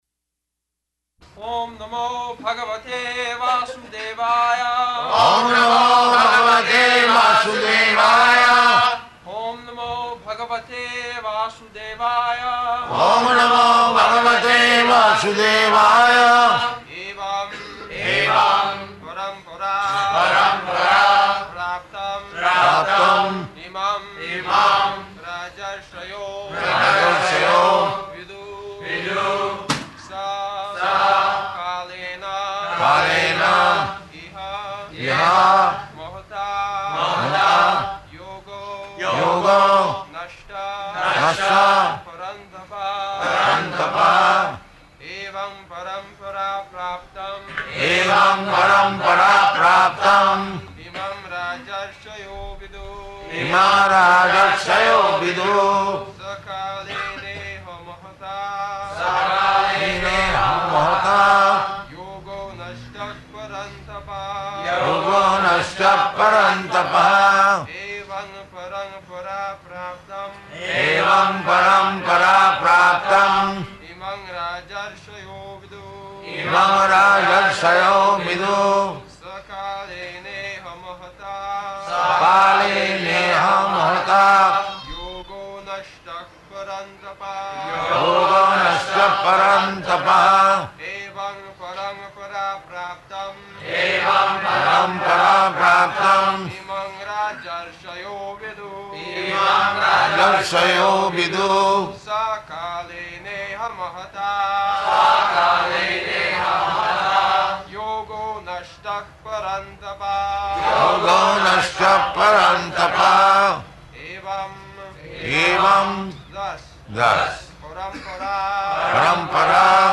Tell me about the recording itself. March 22nd 1974 Location: Bombay Audio file